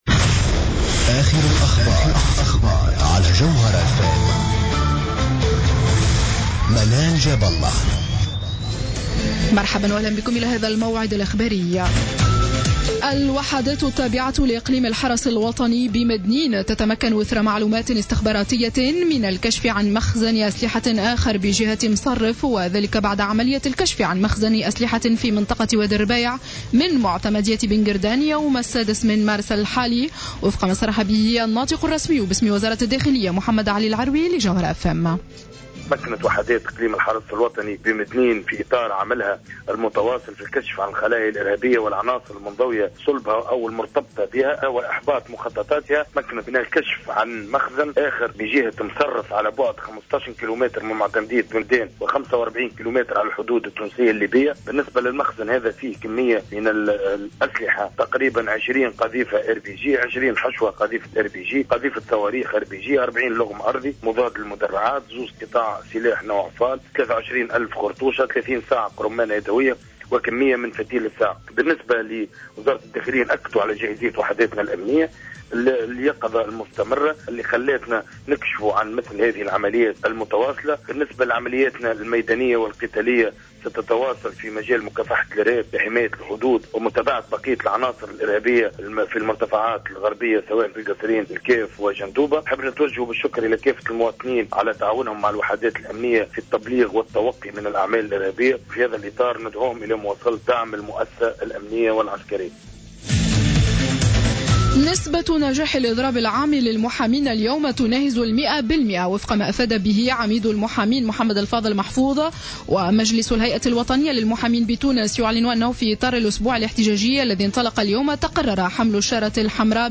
نشرة الأخبار السابعة مساء ليوم الإثنين 9 مارس 2015